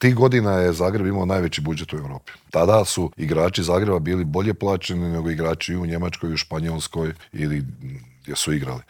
Nakon svjetskog srebra svi se nadaju ponovnom uzletu na smotri najboljih rukometaša starog kontinenta, a o očekivanjima, željama, formi te o tome zašto se u sportu nema strpljenja s trenerima i izbornicima te kako su se nekada osvajale svjetske i olimpijske i klupske titule u Intervjuu Media servisa razgovarali smo bivšim reprezentativcem, legendarnim Božidarem Jovićem.